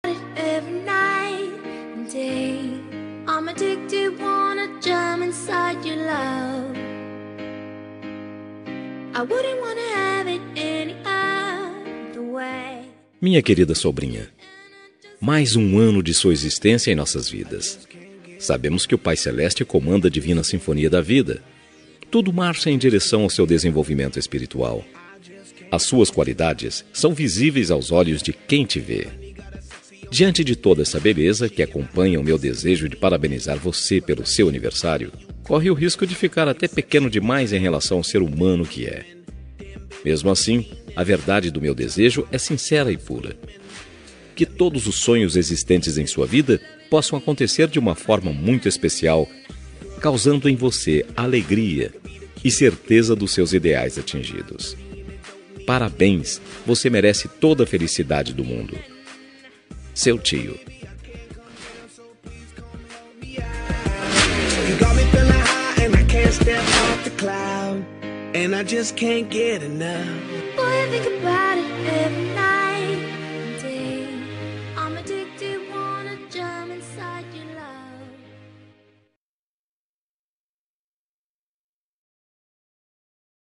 Aniversário de Sobrinha – Voz Masculina – Cód: 4289